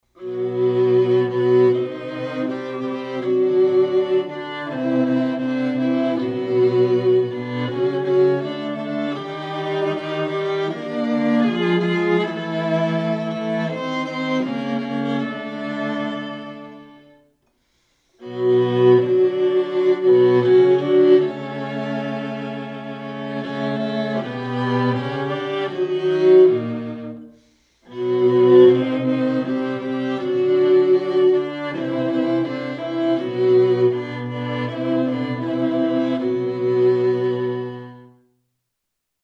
acoustic piece
3 hour session
viola
But the third part i'm not so sure, it's sound more like a Children Song... probably some people would disagree but it's point of view.
Those were pretty much raw treacks yesterday...